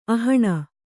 ♪ ahaṇa